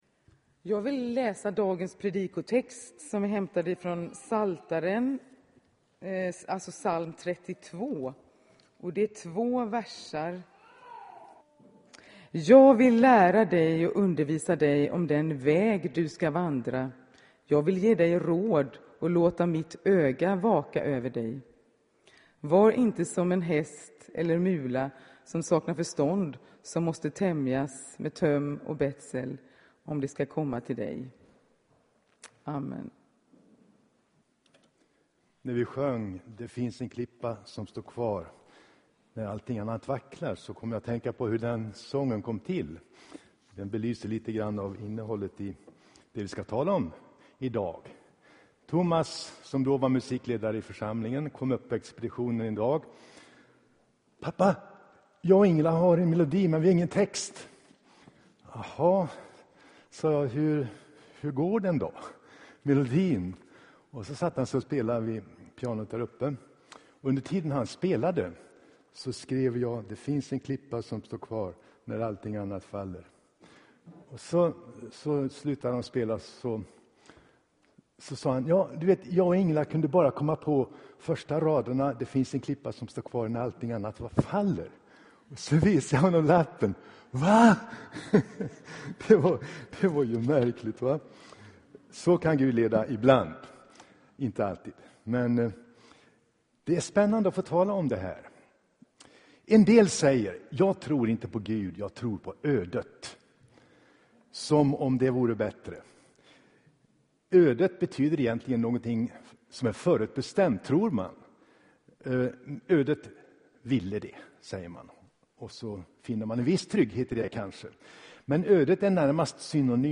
Inspelad i Tabernaklet i Göteborg 2015-09-20.